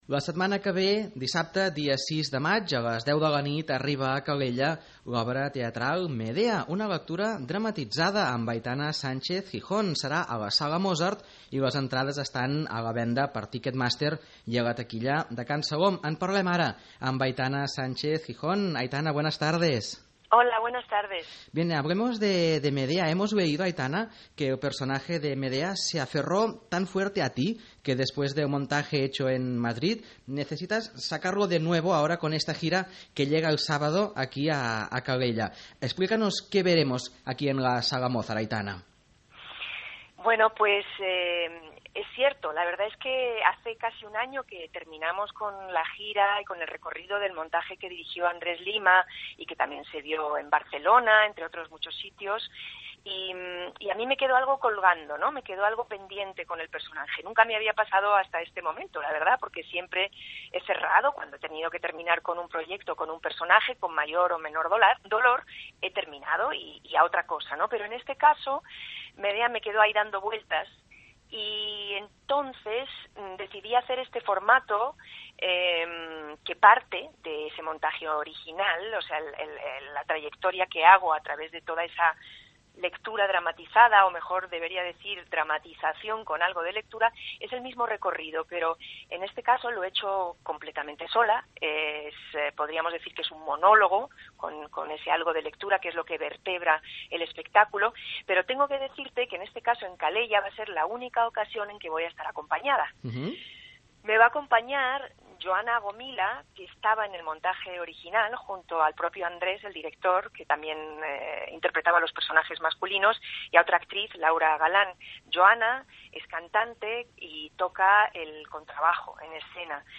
A continuació podeu recuperar l’entrevista íntegra a Aitana Sánchez-Gijón a l’Info Vespre de Ràdio Calella TV.